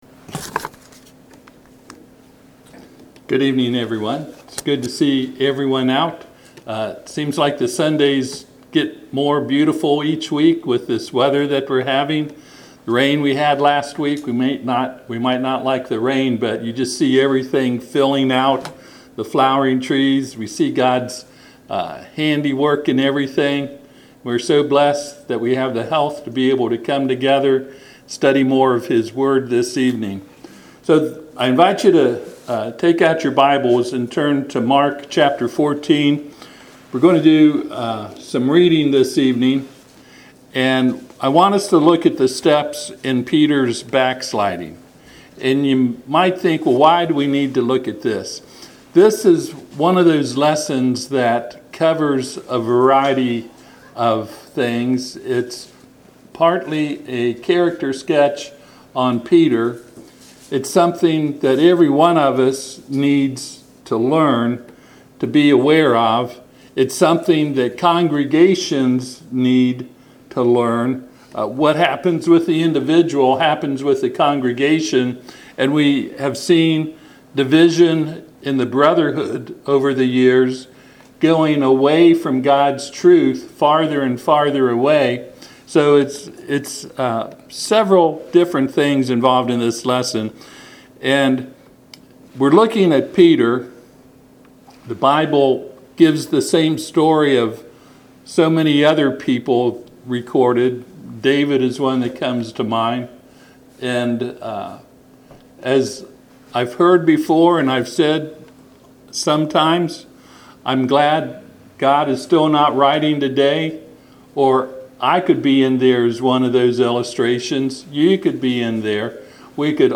Passage: Mark 14:26-72 Service Type: Sunday PM